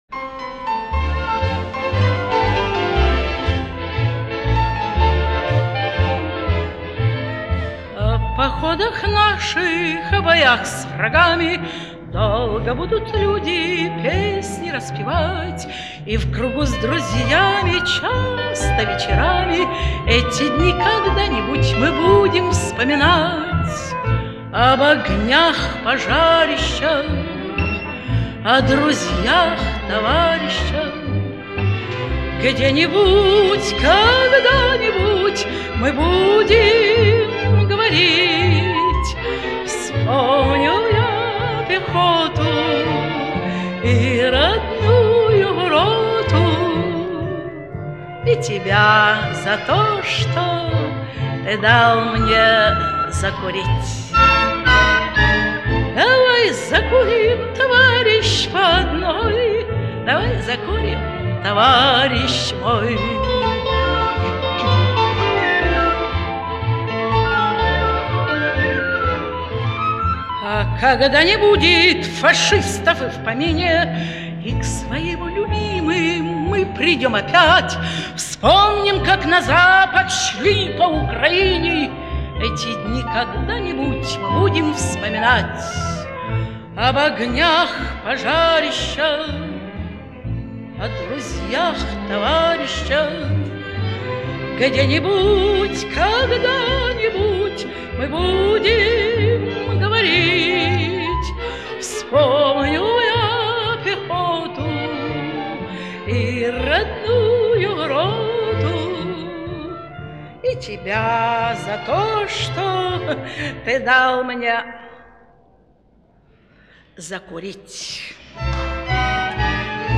Её исполнению присуща глубокая задушевность.